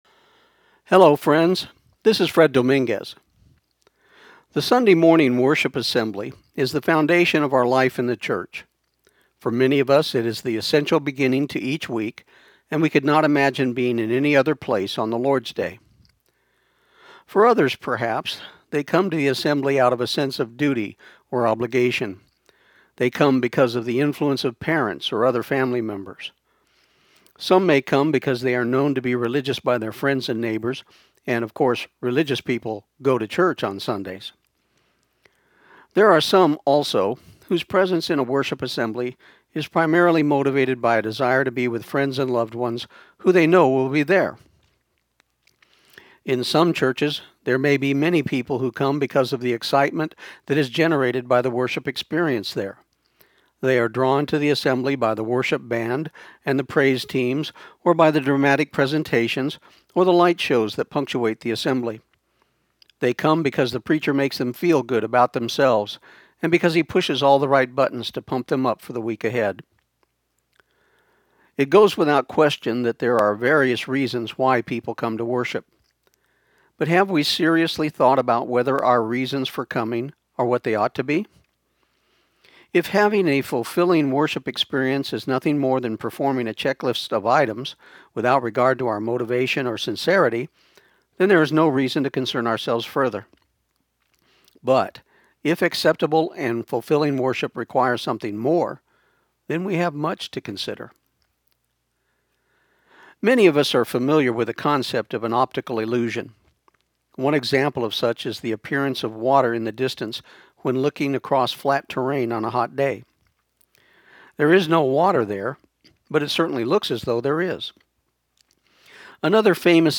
Rubin’s Vase This program aired on KIUN 1400 AM in Pecos, TX on November 3, 2014